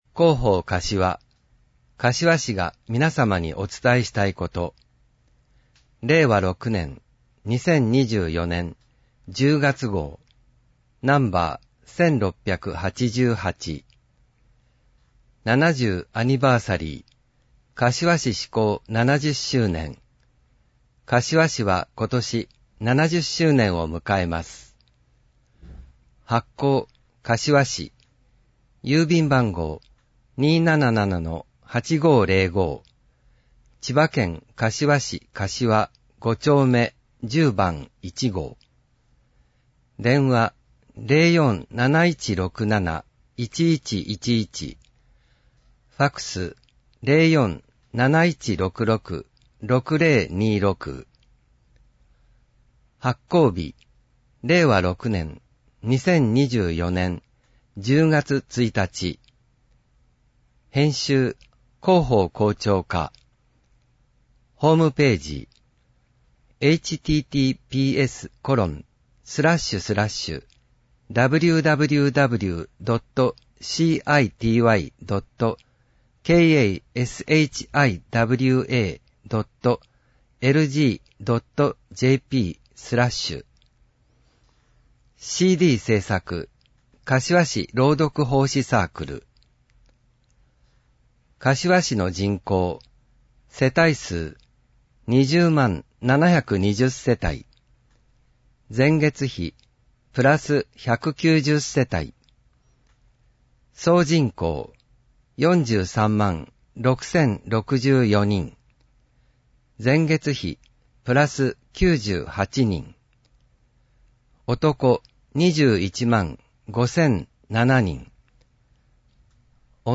令和6年(2024年)10月号音訳版
• 発行は、柏市朗読奉仕サークルにご協力いただき、毎号行っています。